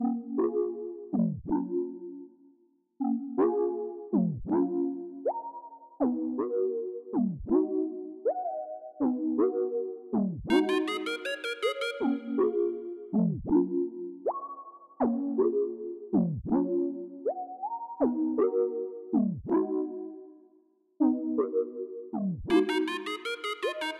陷阱合成器
标签： 80 bpm Trap Loops Synth Loops 4.04 MB wav Key : E
声道立体声